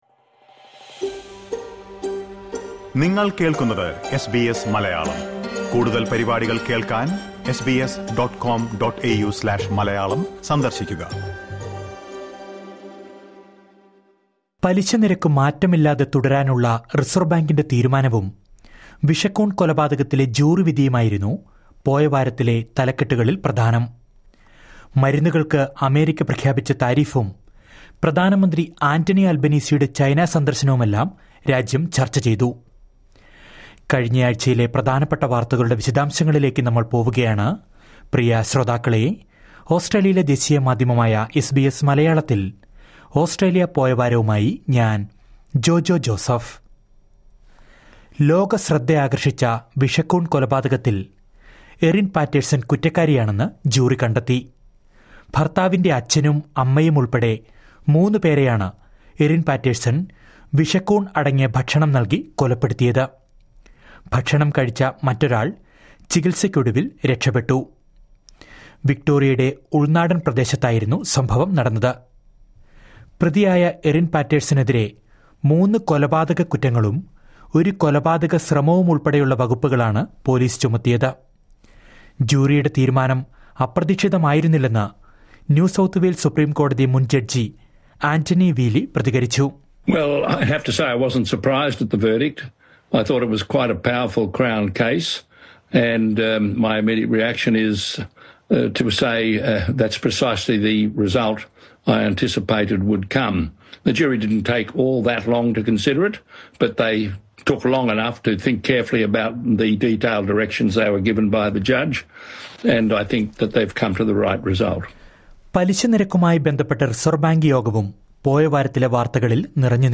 ഇക്കഴിഞ്ഞയാഴ്ചയിലെ ഓസ്‌ട്രേലിയയിലെ ഏറ്റവും പ്രധാന വാര്‍ത്തകള്‍ ചുരുക്കത്തില്‍...